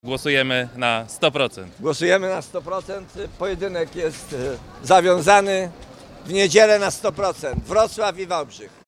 – Głosujemy na 100%! – apelowali zgodnie przedstawiciele obu miast podczas ogłoszenia wyzwania.